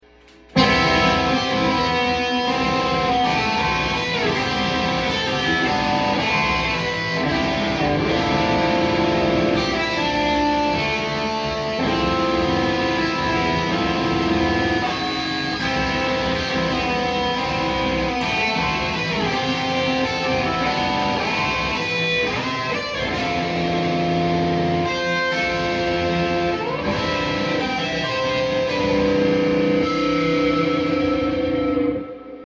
An unused riff in a very sloppy 5/4